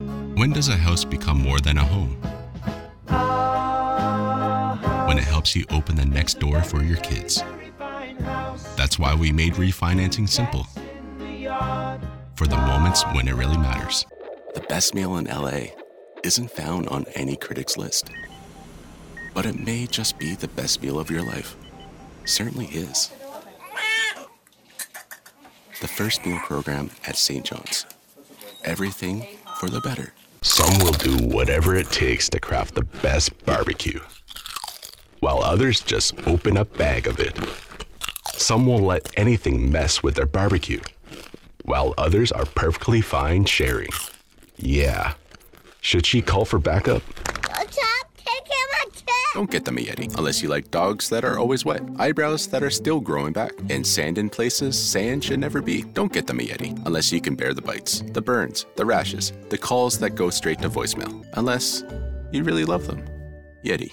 Deep authoritative male voice
Male
Conversational Believable Real